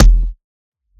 Index of /99Sounds Music Loops/Drum Oneshots/Twilight - Dance Drum Kit/Kicks